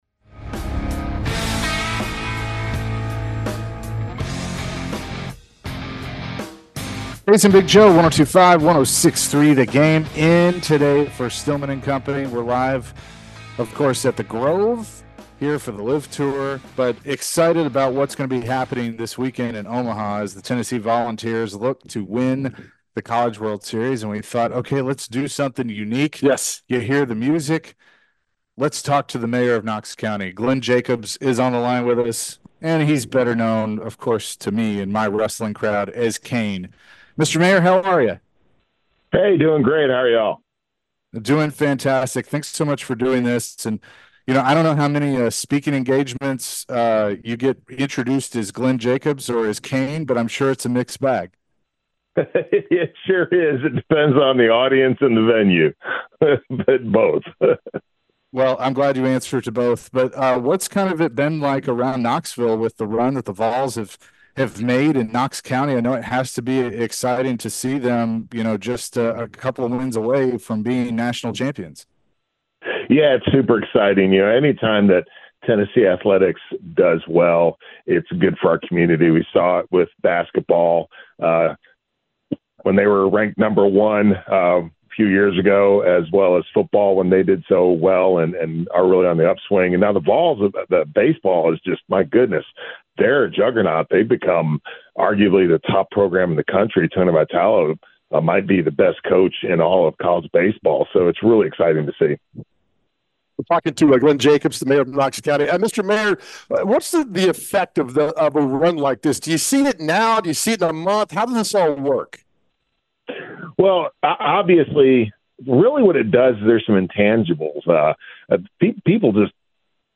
Glenn Jacobs Interview (6-21-24)
Knox Mayor Glenn Jacobs aka Kane from WWE joins the show to talk Vols in Omaha at the CWS, the hype around the team in the city, and of course his time in professional wrestling.